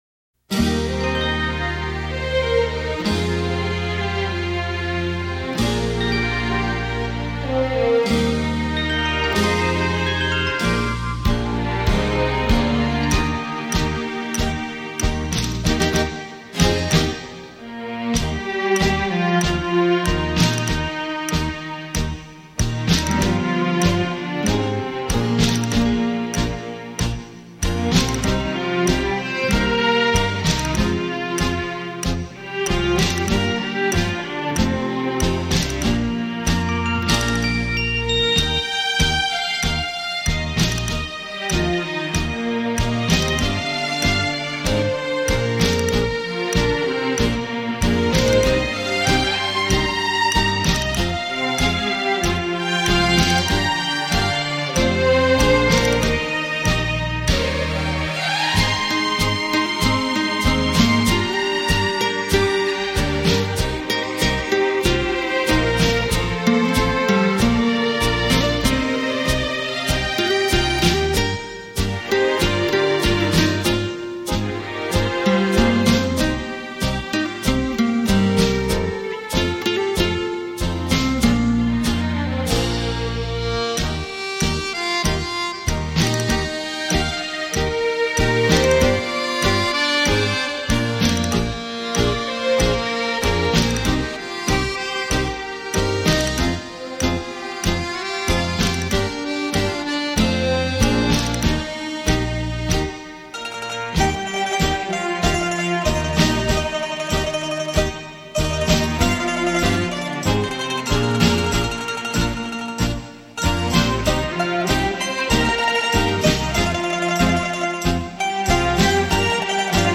重新诠释并融入舞曲风格保证令人耳目一新